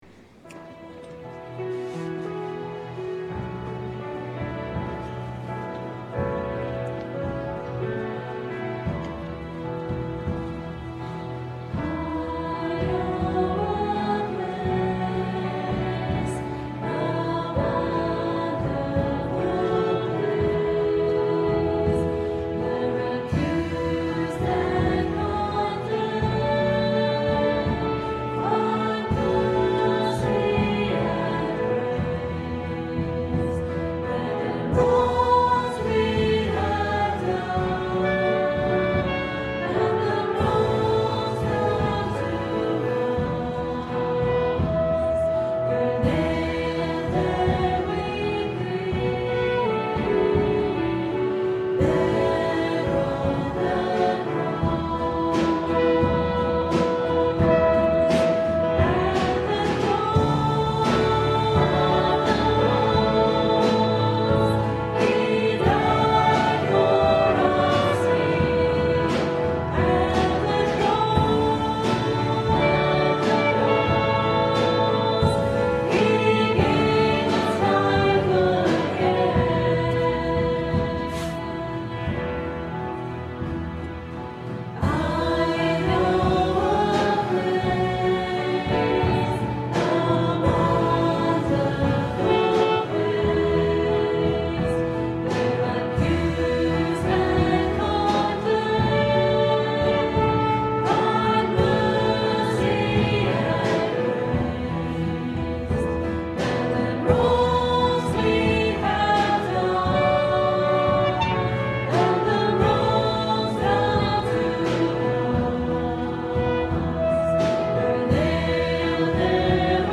Recorded on Sony minidisc, at the Confirmation Mass held at 6pm on Saturday March 5th 2005.